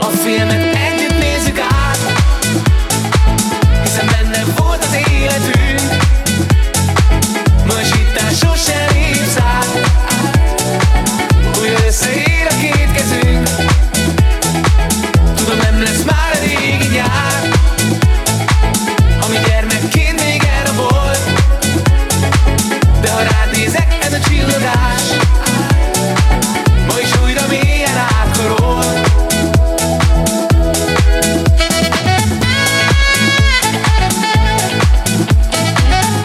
Universal Disco Funky Extended Version